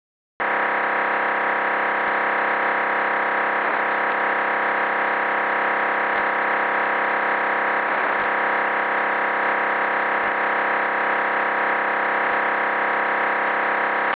Неопознанный сигнал, возможно, - УК транка
просьба опознать сигнал.управляющий канал транка?
Это DMR